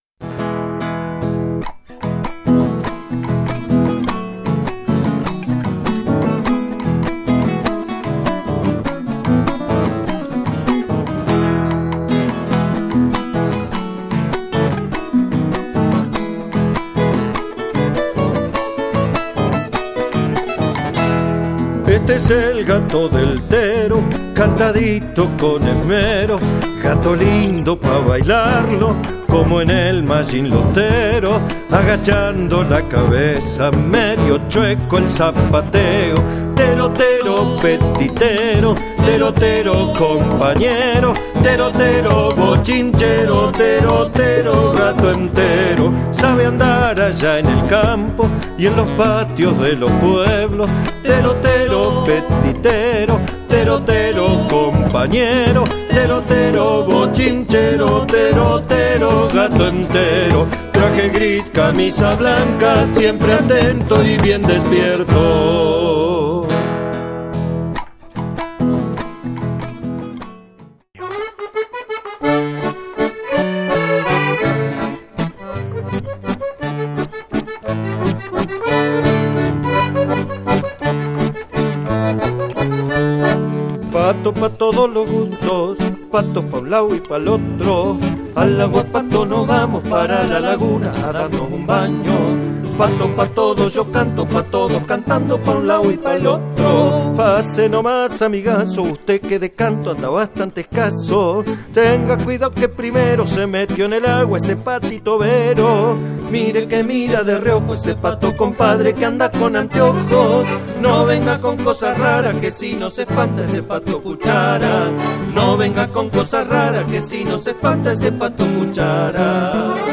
Genero: Folklore